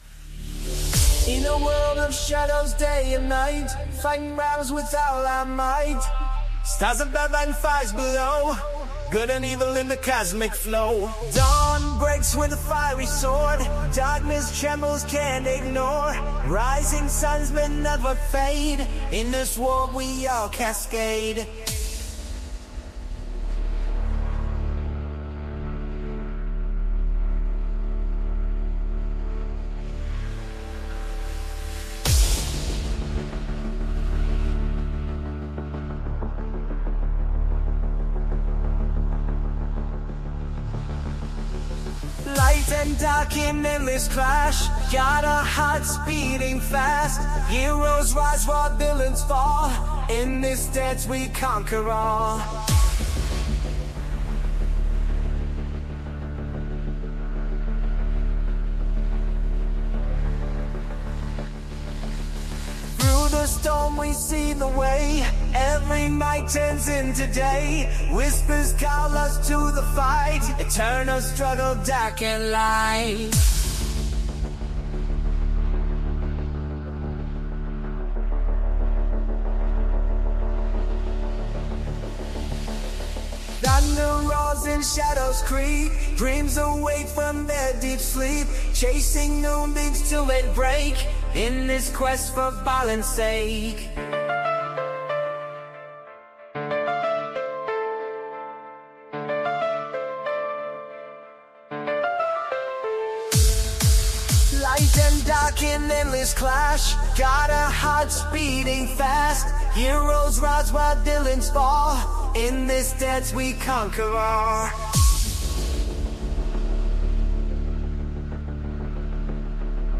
Here is a music I generated with it.